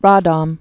(rädôm)